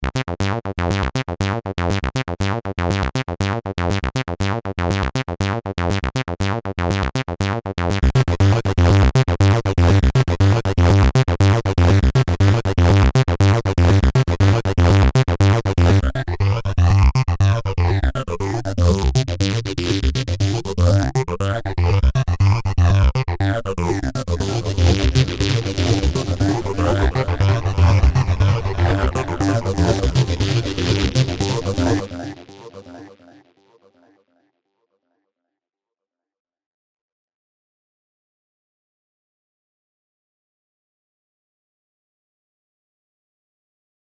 ＜デモサウンド＞
TB-303をシミュレートした音色作成例です。演奏しているフレーズはSpireのアルベジエーターによるものです。また、フレーズは同じフレーズを繰り返していますが、4小節ごとにCUBASEのオートメーションでエフェクトをシェイパー → フェイザー → ディレイの順で追加しています。
spire_soundmake_034_demo.mp3